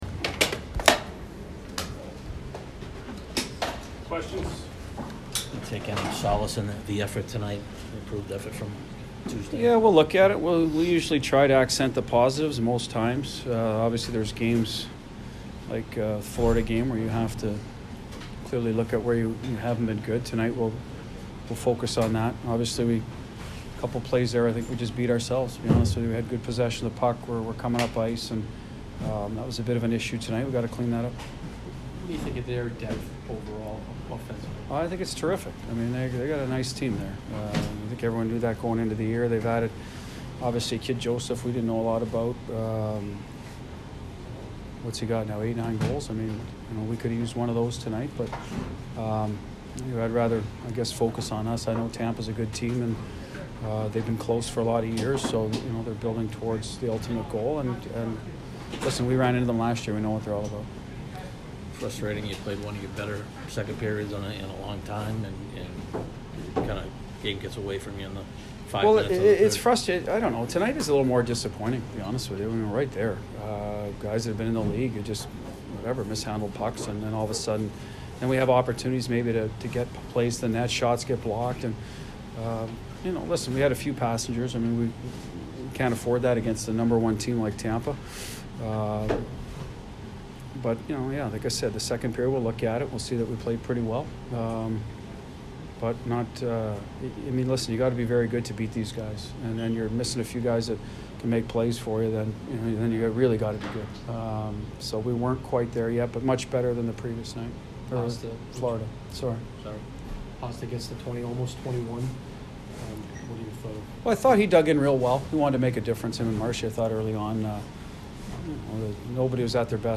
Bruce Cassidy post-game 12/06
Bruce Cassidy post-game 12/06 by Tampa Bay Lightning